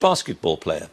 As before, the noun is accentuated if it’s described by a preceding adjective, but not accentuated if it does something to a preceding noun:
BASKETBALL player (player of basketball)